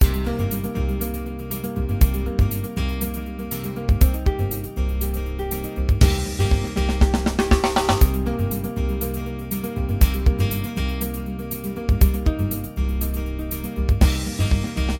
backing tracks
Aeolian Mode